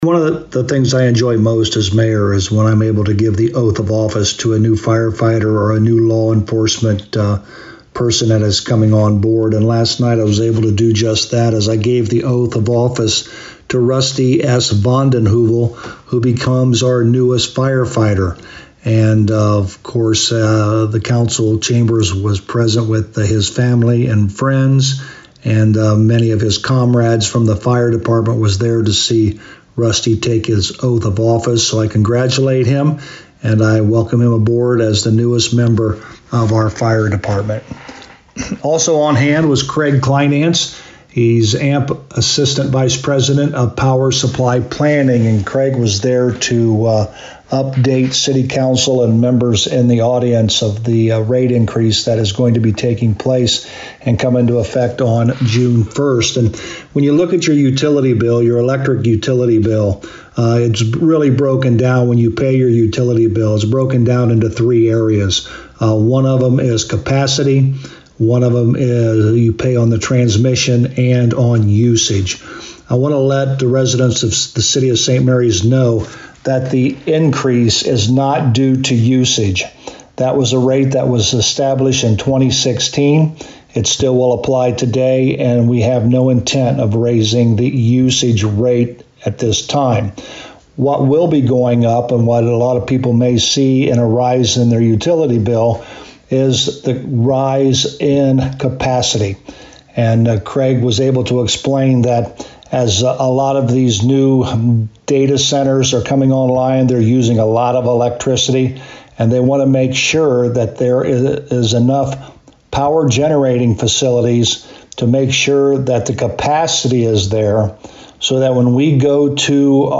The St Marys City Council met Monday Night May 12th. For a summary with St Marys Mayor Joe Hurlburt: